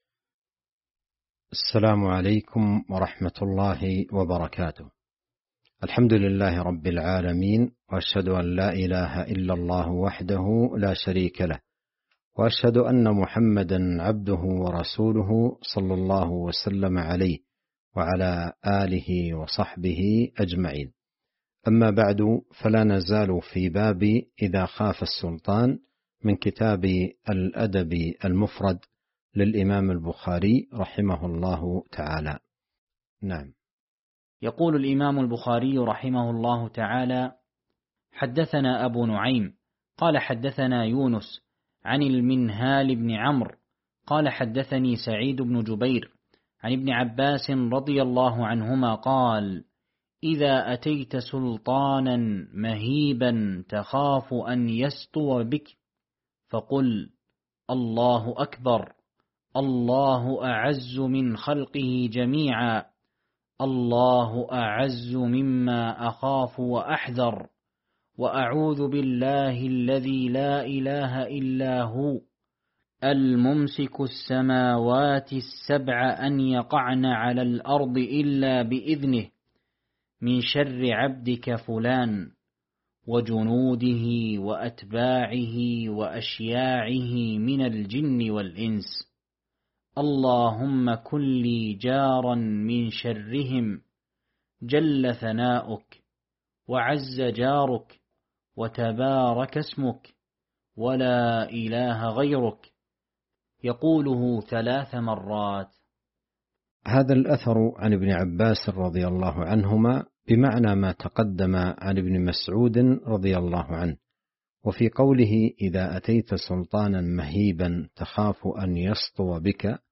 شرح الأدب المفرد الدرس 232